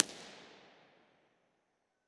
Location: Washington DC Investigator
Description: Main gallery.
Loudspeaker: Frazier CAT40 + 12″ LF woofer Microphone: XY Cardioid
Source: 14 sec Log Sweep
Room Impulse Responses
IR_TP2_40ft_CAT40.wav